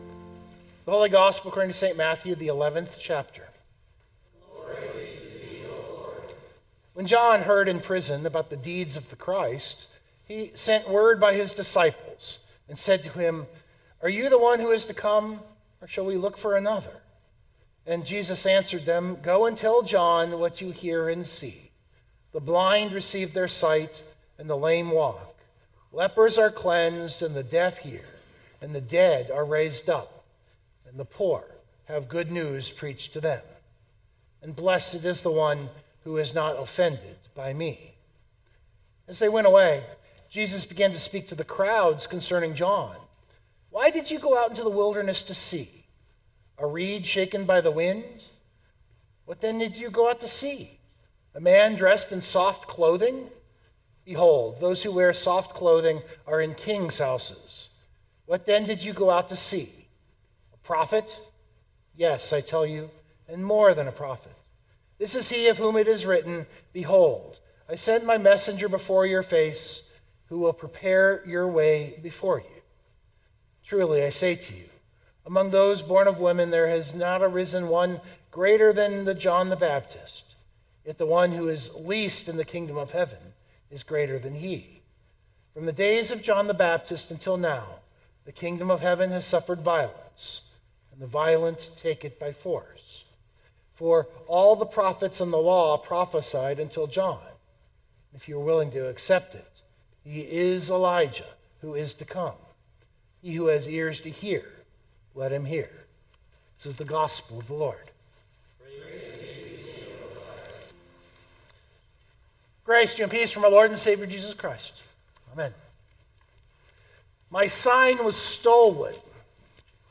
121425 Sermon Download Biblical Text: Matthew 11:2-15 This is one of my favorite texts in the lectionary.